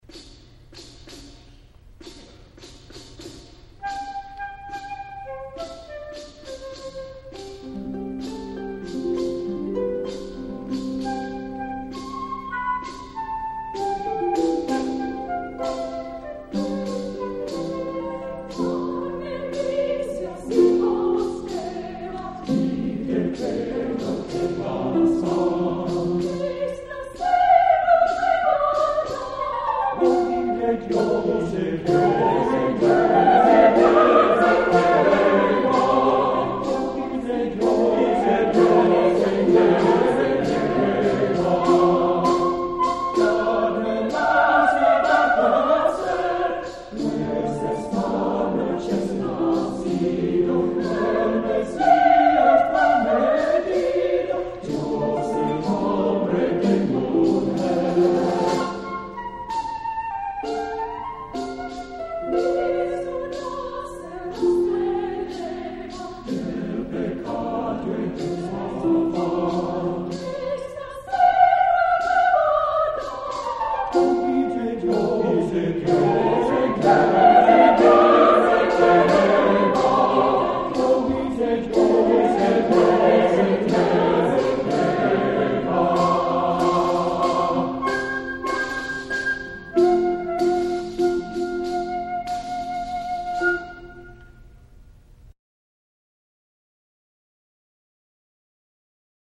Voicing: SATB,Harp,